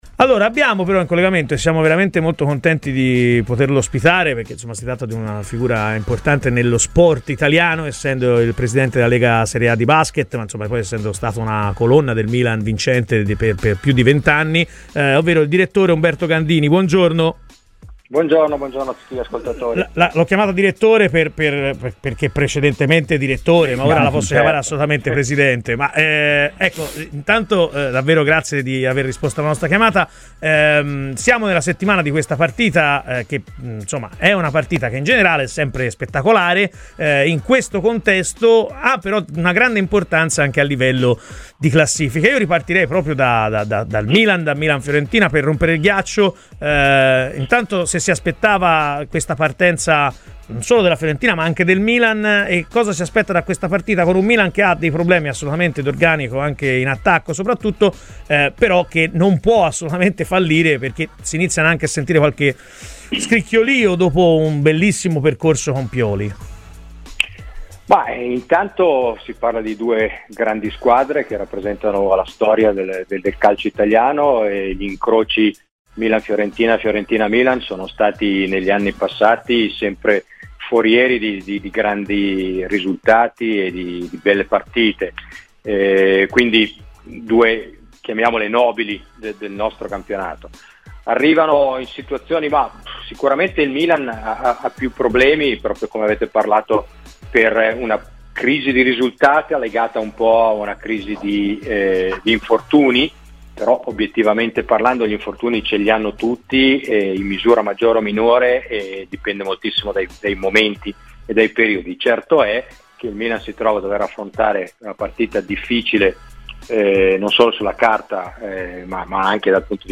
Ospite di Radio FirenzeViola nel corso di “Chi si compra?”